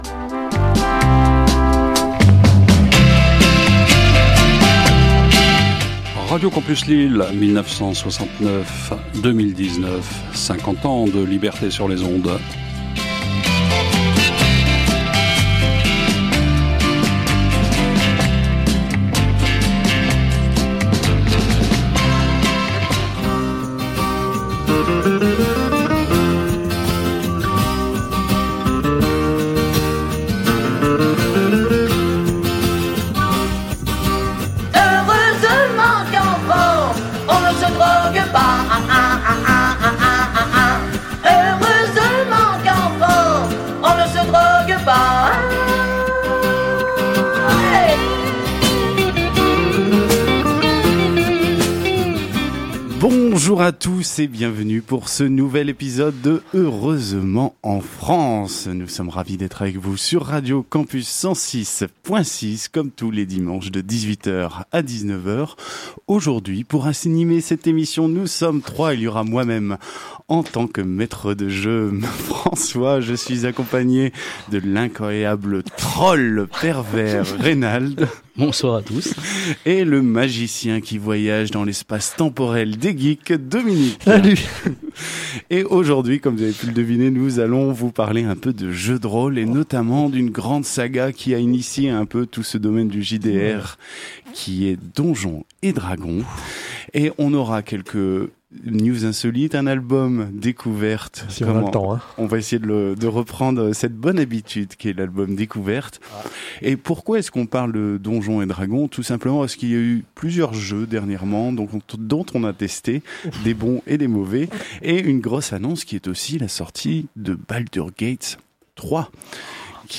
Au sommaire de cet épisode diffusé le 16 Juin 2019 sur Radio Campus 106,6FM :